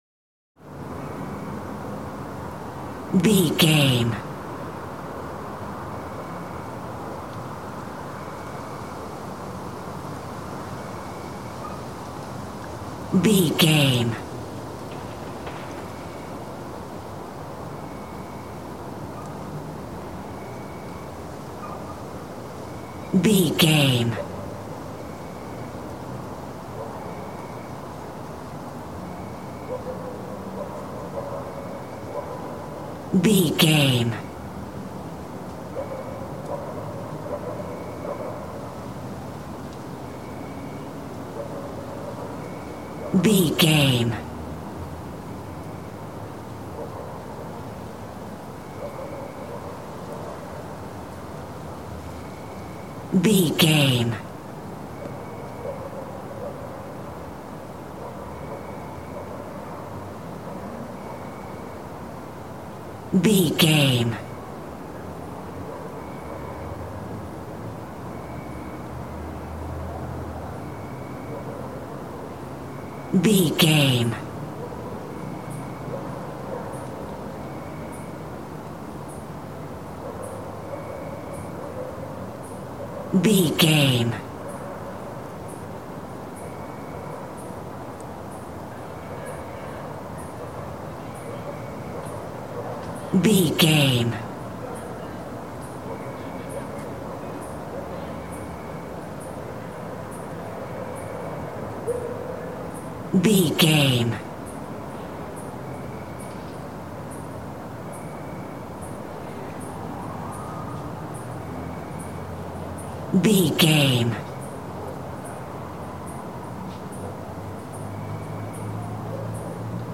City rumble wind night
Sound Effects
Atonal
chaotic
scary
urban
ambience